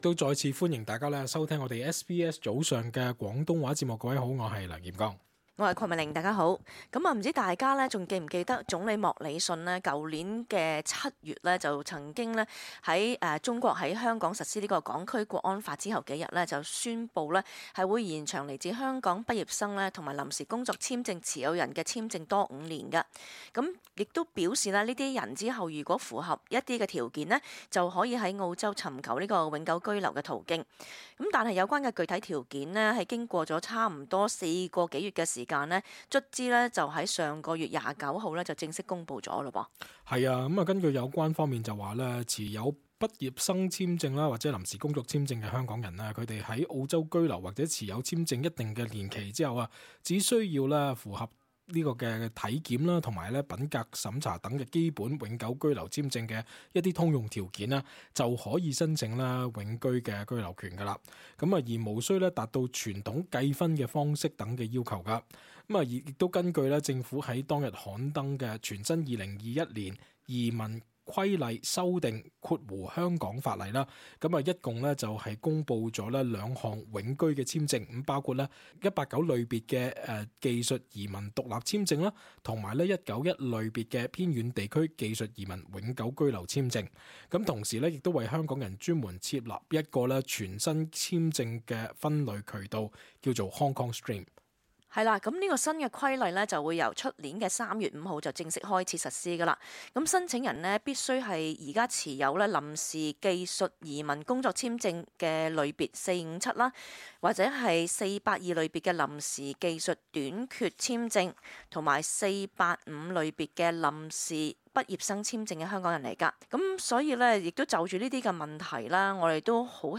cantonese_-_talkback_-_nov_11_-_final_upload.mp3